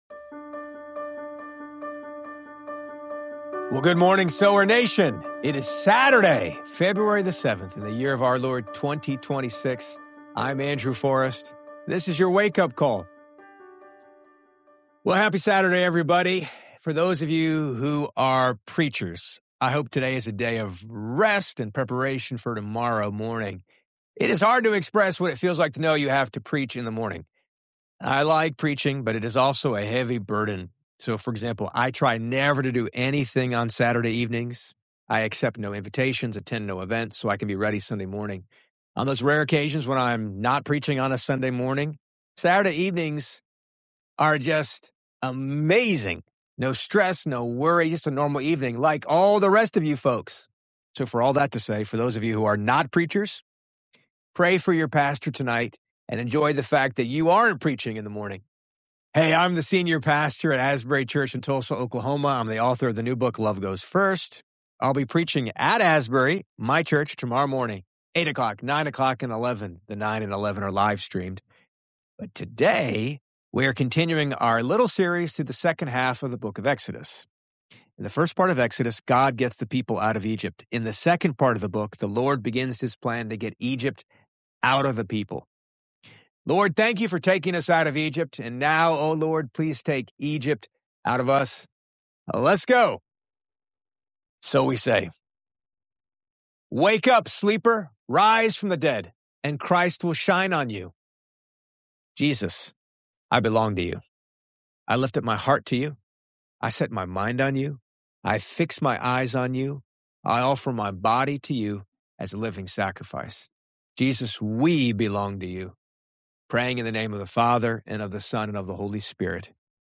You’ll also be uplifted by a stirring rendition of the hymn “Seek Ye First,” reminding us of the enduring power of simplicity and trust in God’s promises.